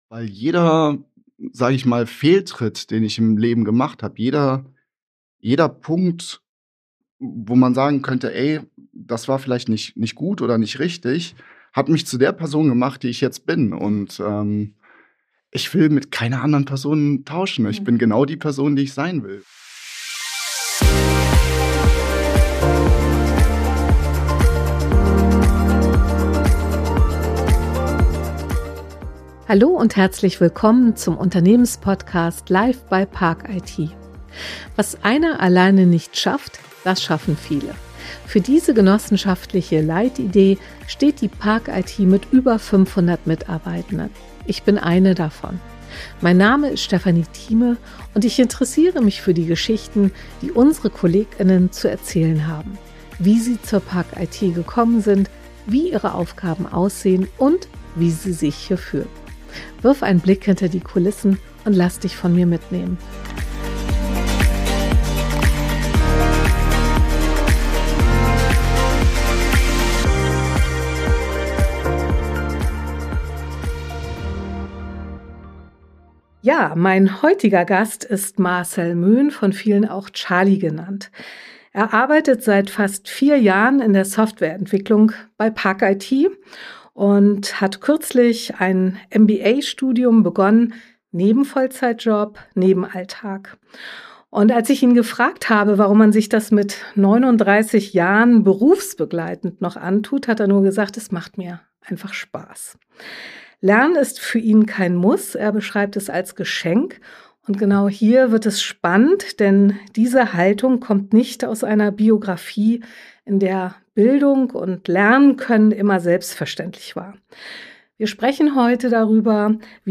Es geht um seinen ungewöhnlichen Bildungsweg, den Mut, den eigenen Weg zu gehen, und eine Haltung, die Lernen als Chance begreift. Ein Gespräch über Selbstvertrauen, Entwicklung und die Frage, was möglich wird, wenn man aufhört, sich selbst zu begrenzen.